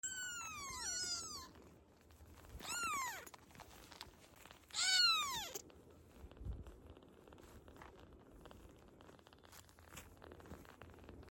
Hear Those Lil Purrs? 😭 Sound Effects Free Download